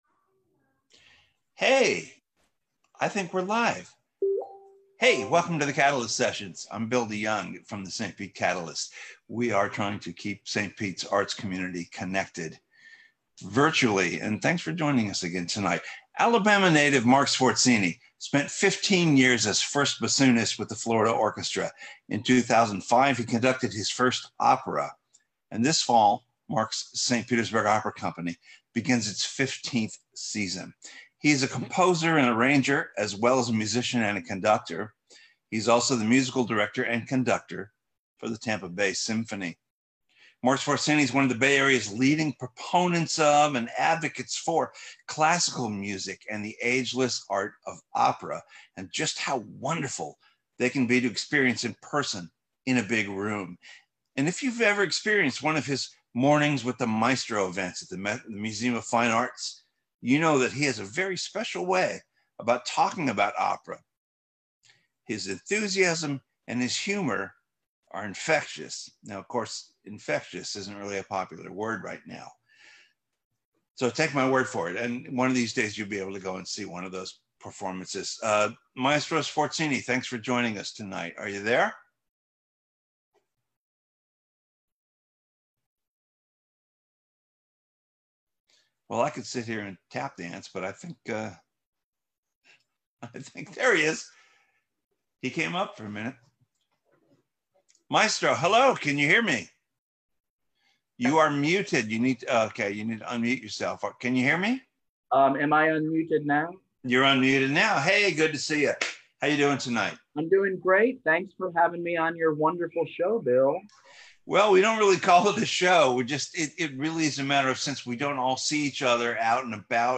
a live, streaming interview